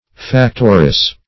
Factoress \Fac"tor*ess\
factoress.mp3